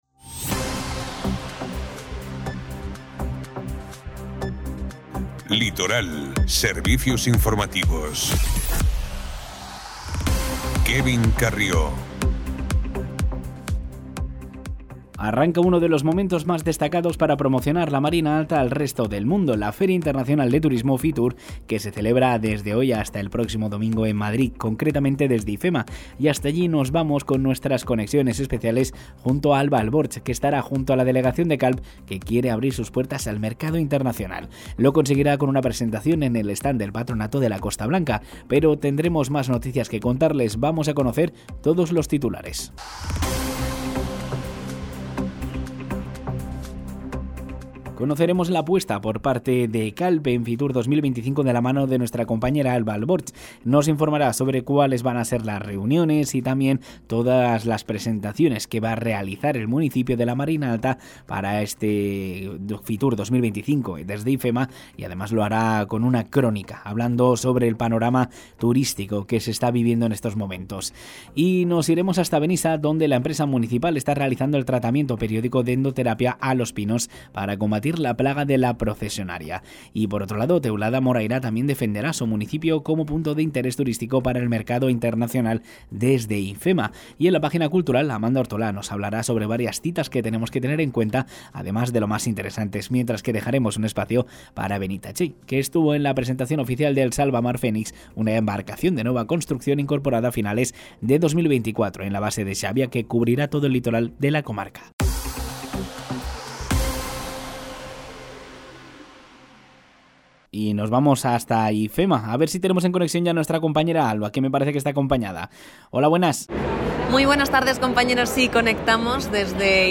A FITUR 2025, Calp està deixant una empremta destacada amb dues de les seves propostes més representatives: la Setmana Santa calpina i el projecte de restauració i visita al conjunt arqueològic dels Banys de la Reina. L'alcaldessa Ana Sala ha compartit en una entrevista amb Ràdio Litoral els detalls d'aquestes iniciatives que continuen posicionant la ciutat com un destí turístic únic i culturalment ric.